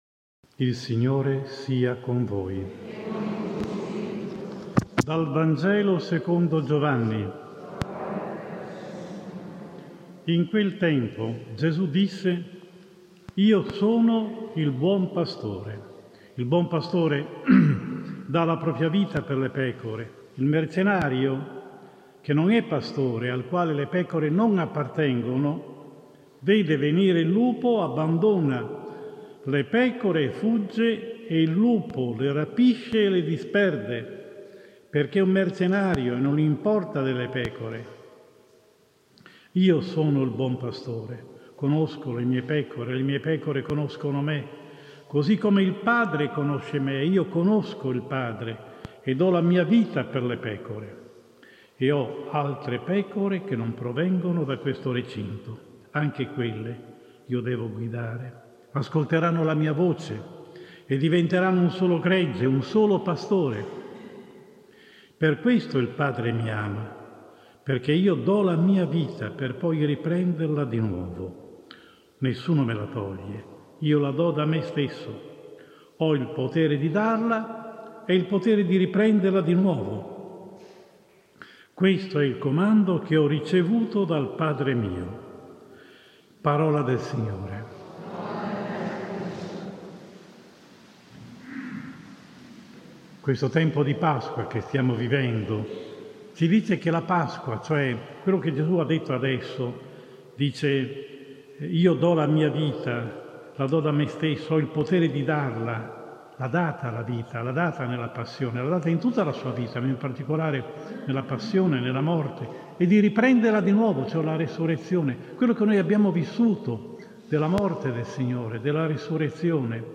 Domenica 2 febbraio 2010 Presentazione del Signore – omelia